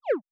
SFX_Dialog_Close_05.wav